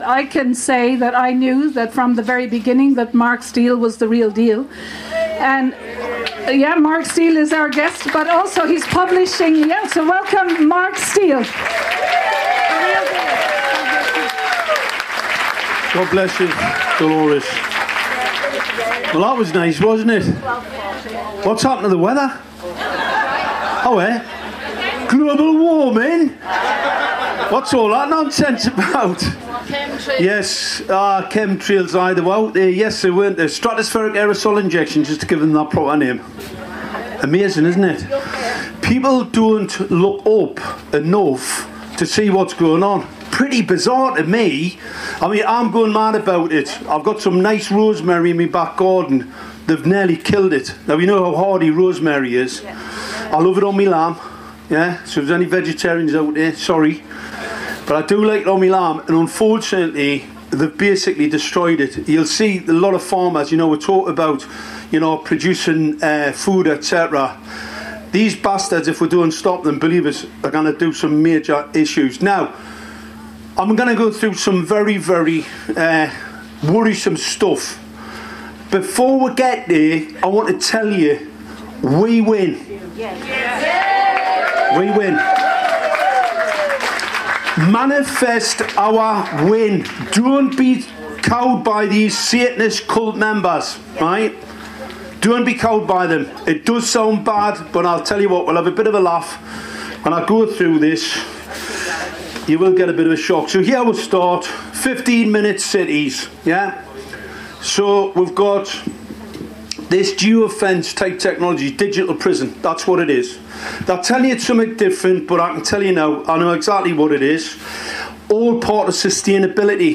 the 'Weekend Truth Festival' Cumbria, UK